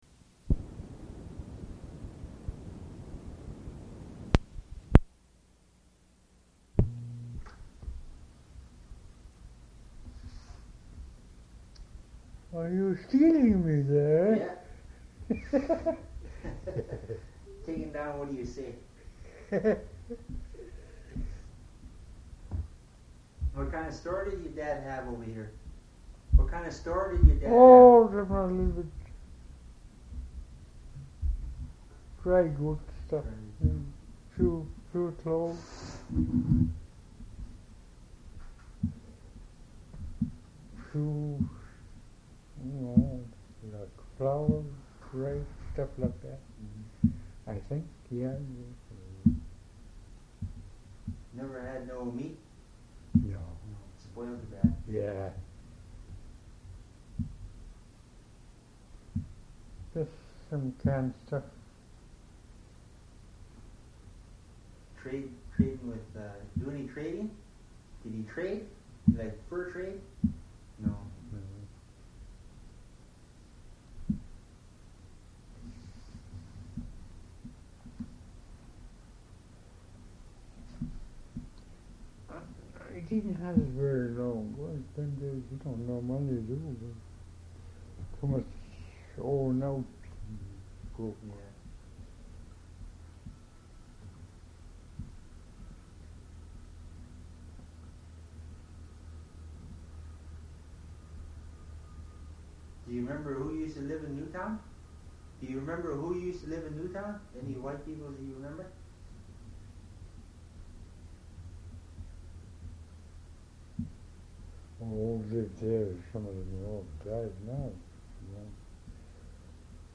Audio non-musical
oral histories (literary works)